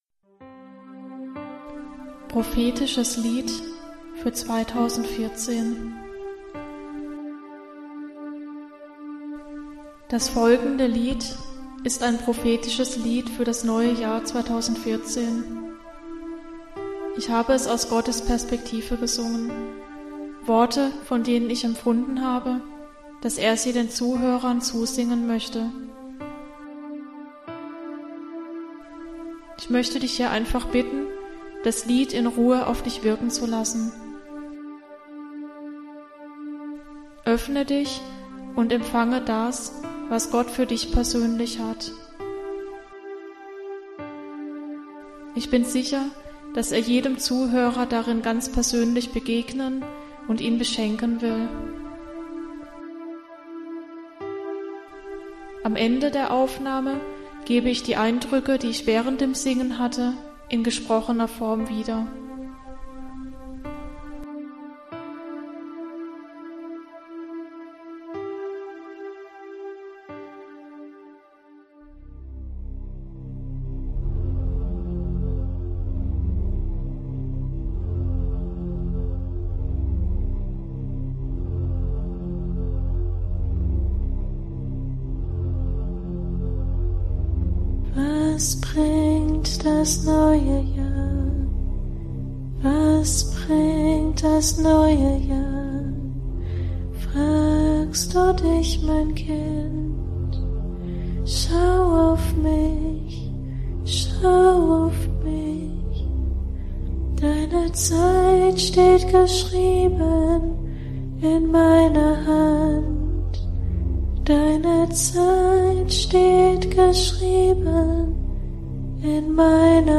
prophetisches-lied-fuer-20142.mp3